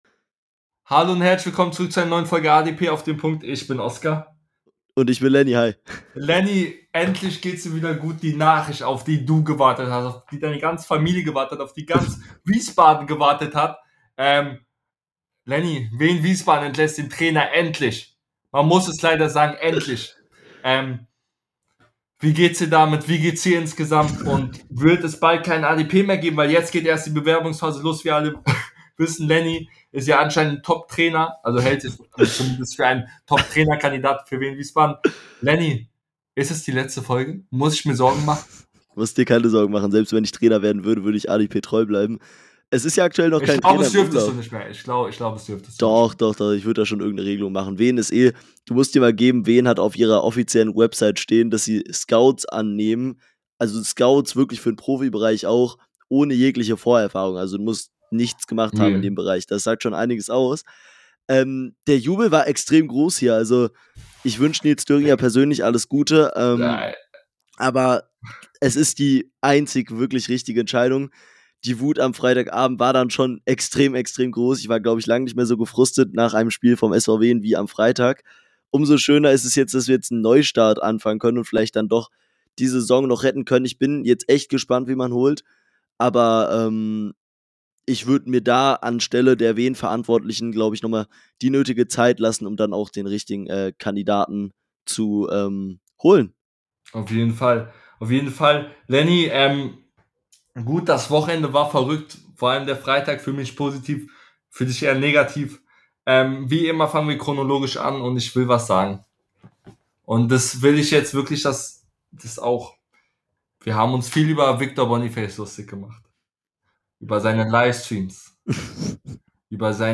In der heutigen Folge sprechen die beiden Hosts über die die vielen Selbstdarsteller in der Bundesliga , gucken in den Tabellenkeller , blicken auf den DFB Pokal und vieles mehr